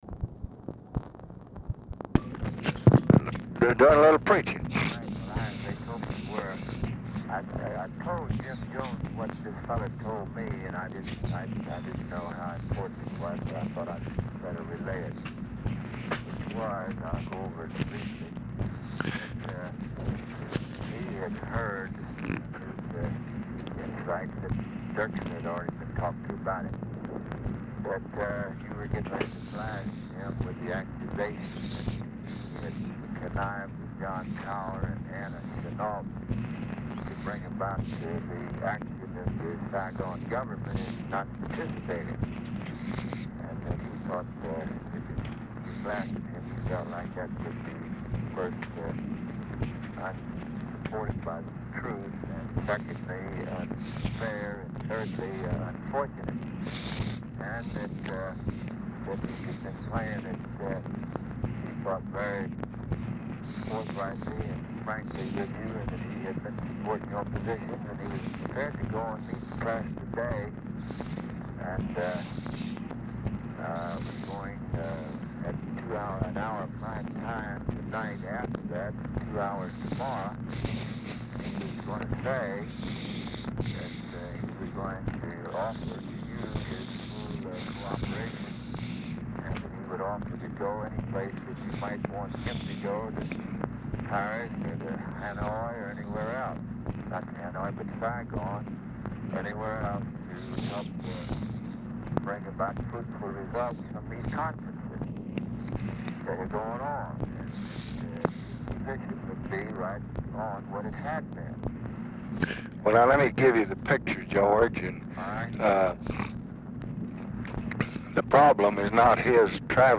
RECORDING STARTS AFTER CONVERSATION HAS BEGUN; CONTINUES ON NEXT RECORDING
Format Dictation belt
Location Of Speaker 1 LBJ Ranch, near Stonewall, Texas
Specific Item Type Telephone conversation Subject Communist Countries Congressional Relations Defense Diplomacy Elections Investigations National Politics Press Relations Lbj Speeches And Statements Ussr And Eastern Europe Vietnam Vietnam Criticism